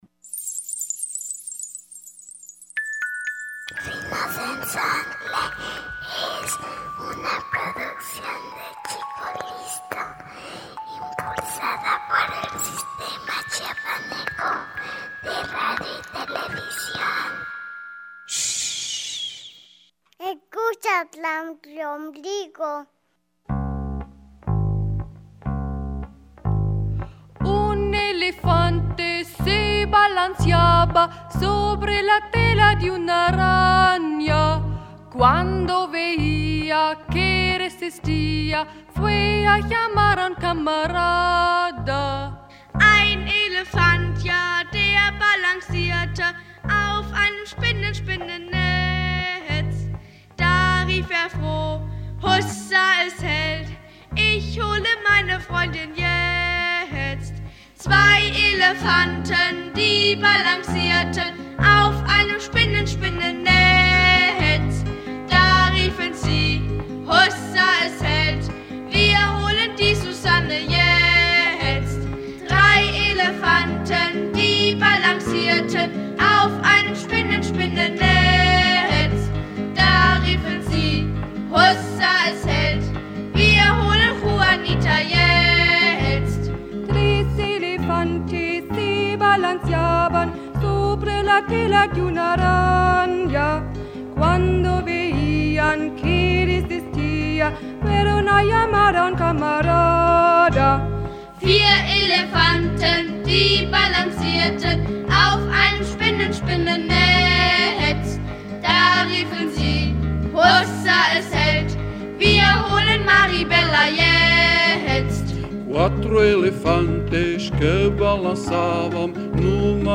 Hoy daremos un paseo musical, sobre los lomos del Rinocenzontle, por varias regiones del planeta.